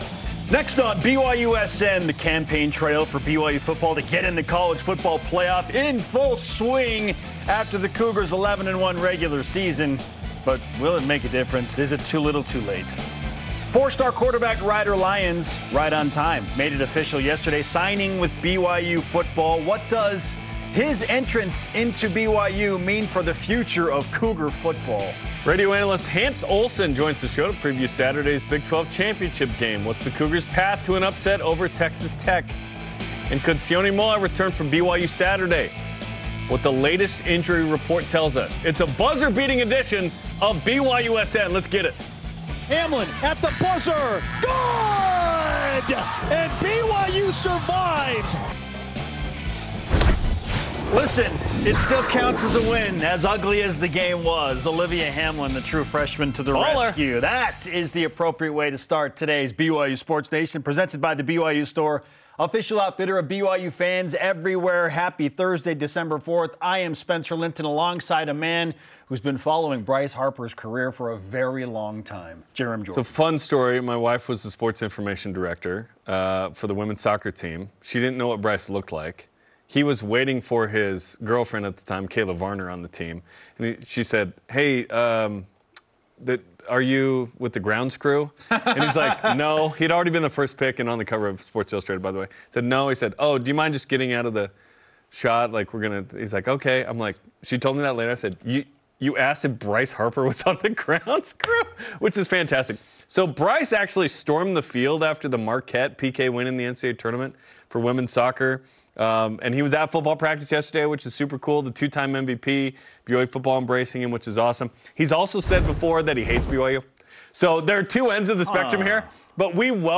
You’ll get in-depth play analysis, athlete interviews, and gripping commentary on all things BYU Football, Basketball, and beyond.